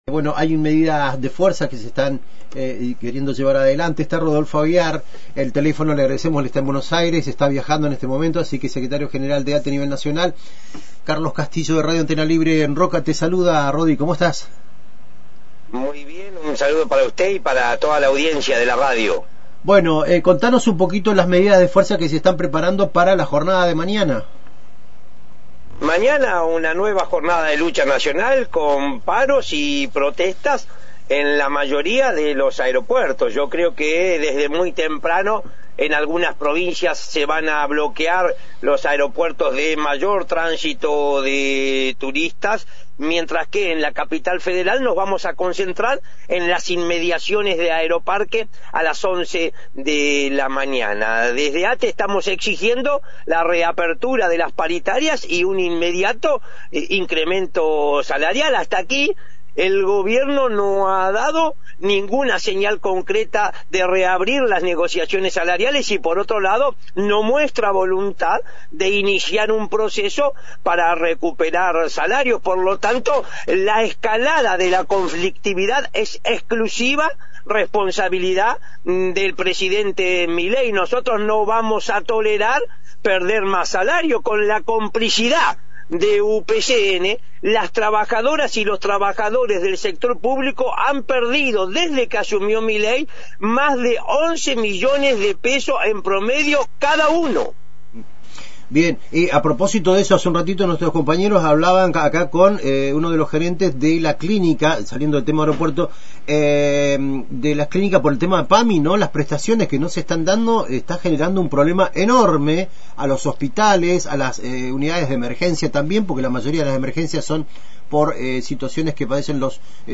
El secretario general de ATE, Rodolfo Aguiar, confirmó en Antena Libre una nueva jornada nacional de lucha con paros y protestas en la mayoría de los aeropuertos del país.